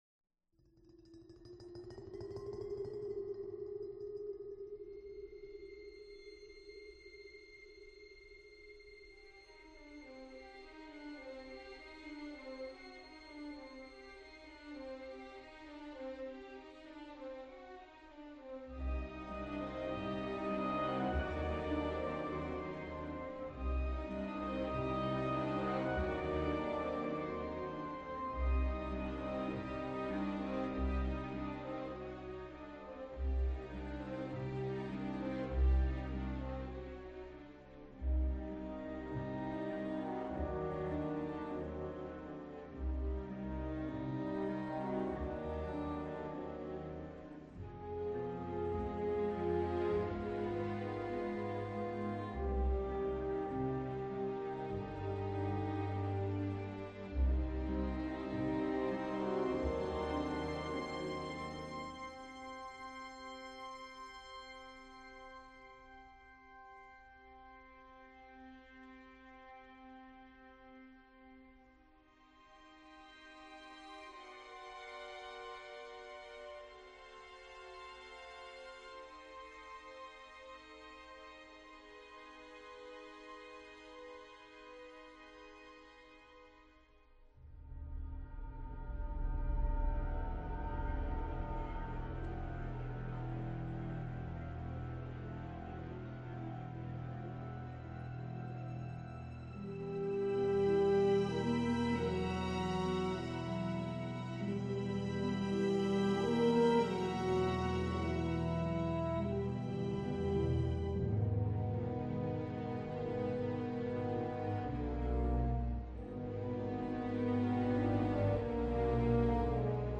Soundtrack, Orchestral